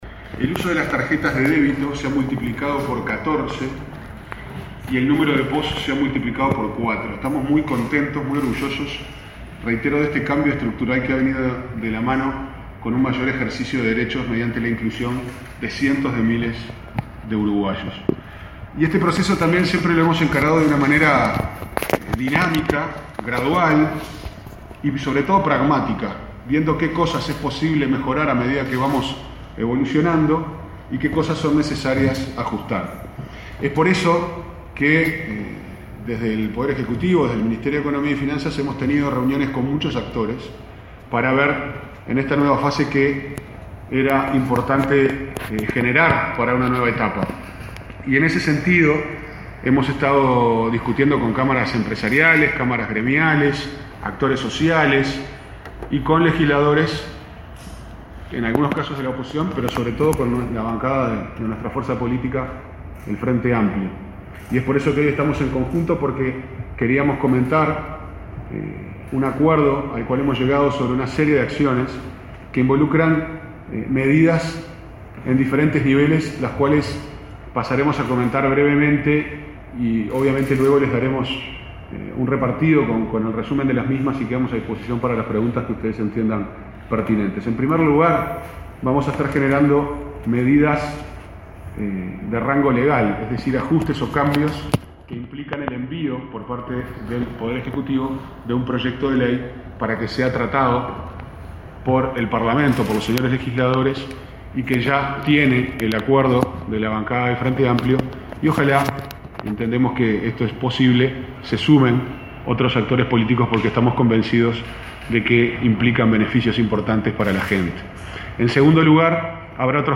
El subsecretario de Economía, Pablo Ferreri, anunció en el Parlamento avances en la Ley de Inclusión Financiera.